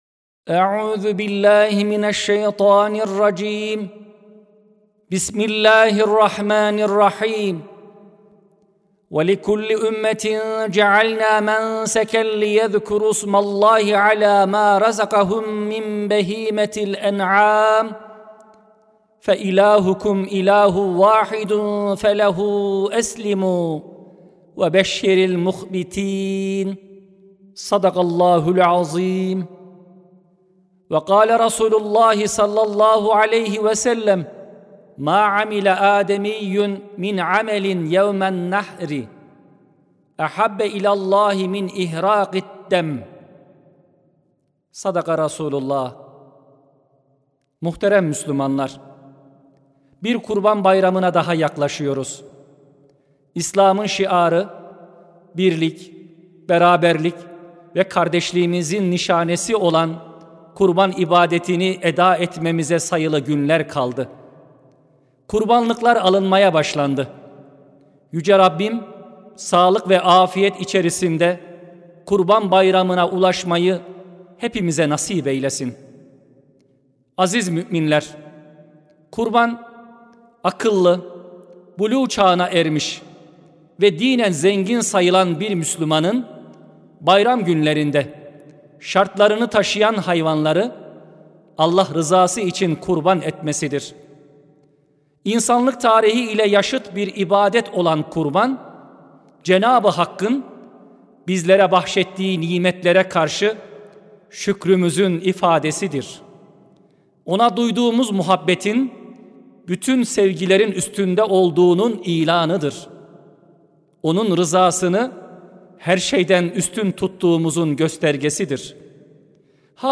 23.05.2025 Cuma Hutbesi: Sadakat ve Teslimiyetin Nişanesi: Kurban (Sesli Hutbe, Türkçe, Arapça, İngilizce, İspanyolca, Almanca, İtalyanca, Rusça, Fransızca)
Sesli Hutbe (Sadakat ve Teslimiyetin Nişanesi, Kurban).mp3